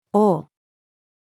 王-female.mp3